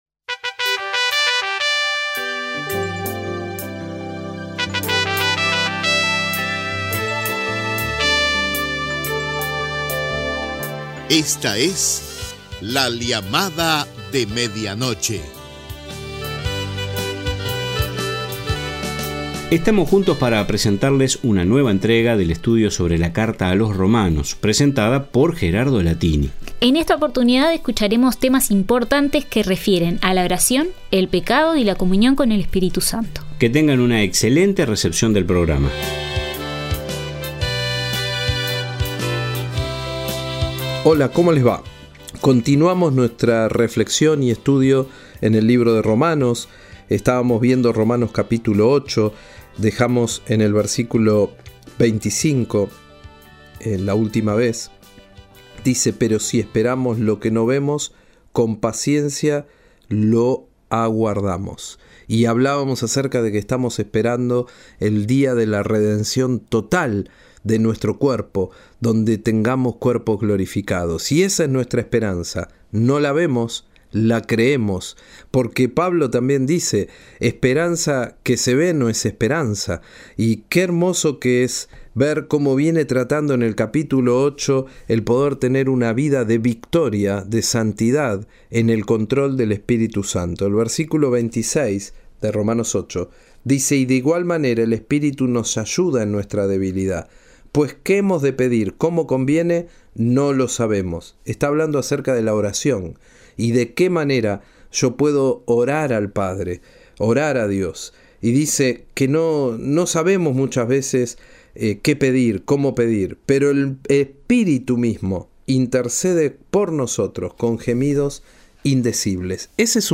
Próximamente transcripción de la entrevista